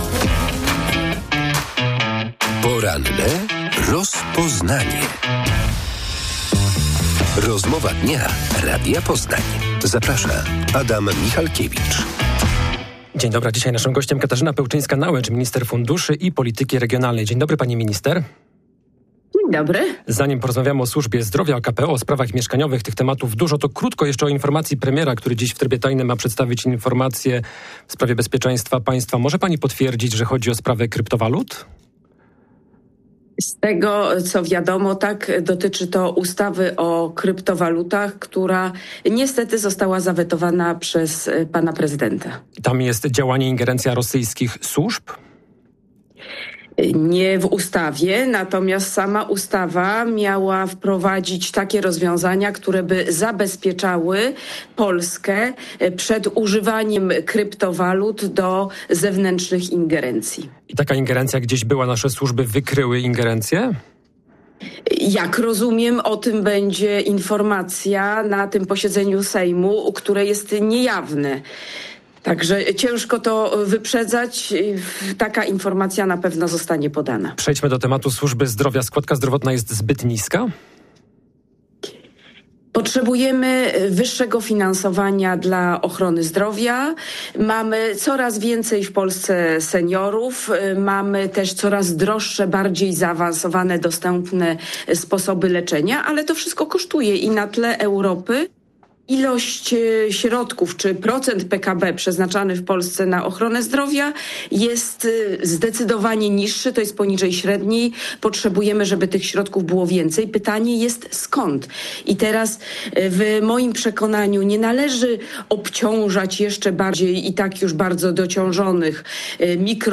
Potrzebujemy wyższego finansowania dla ochrony zdrowia - powiedziała w porannej rozmowie Radia Poznań minister funduszy i polityki regionalnej Katarzyna Pełczyńska-Nałęcz.